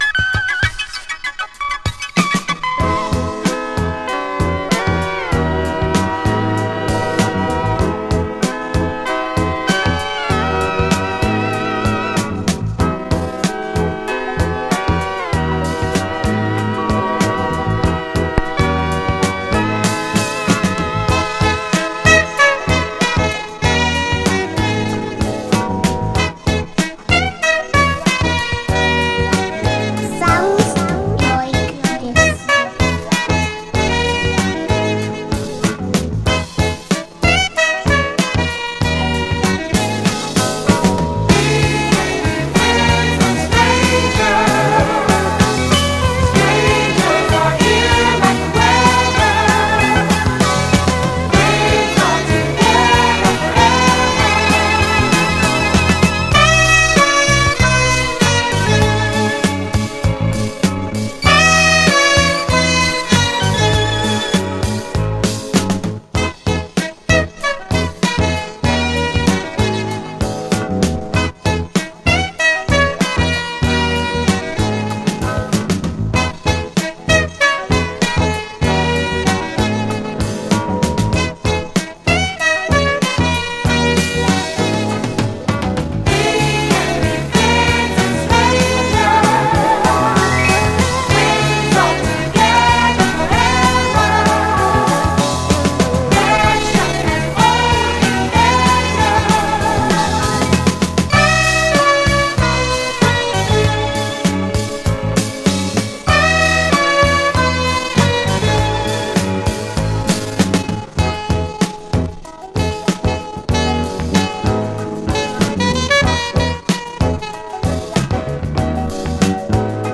Roastin some leftfield dance type music!